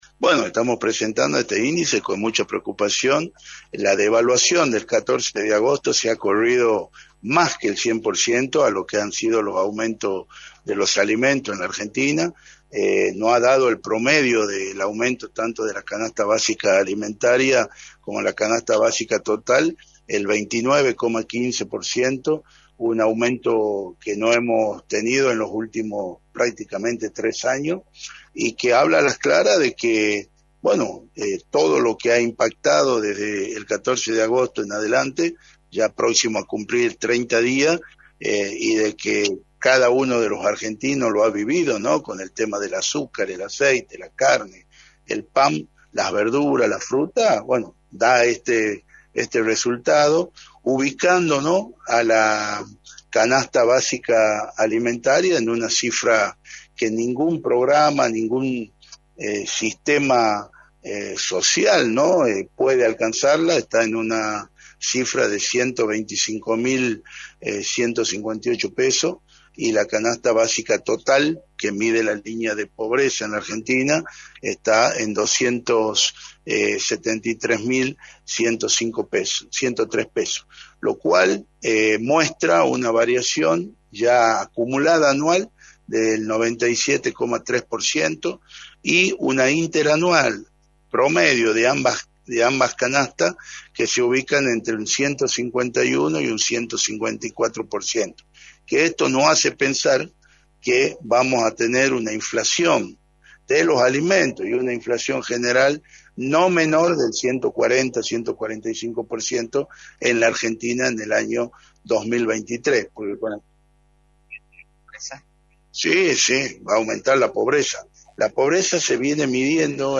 Federico Masso, Legislador electo, remarcó en Radio del Plata, por la 93.9, cuáles son las repercusiones en la sociedad luego de la presentación del índice barrial de precios de agosto.